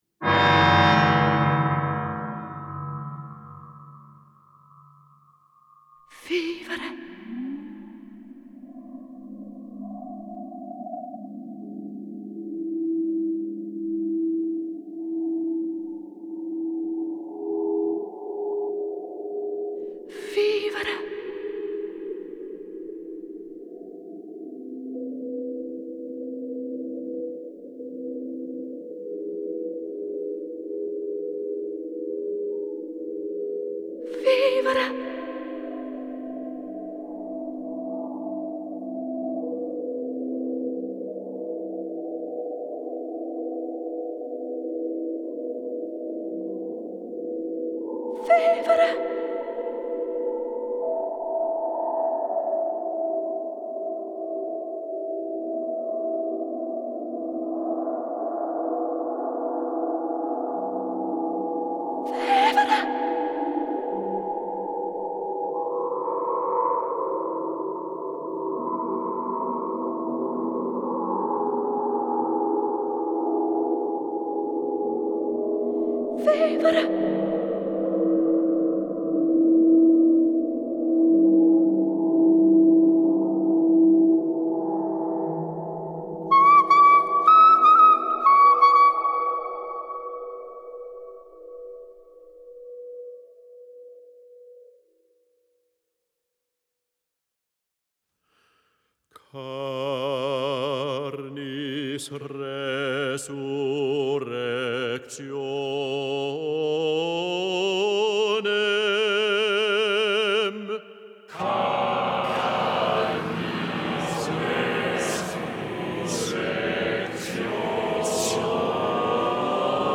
with each section rising and rising.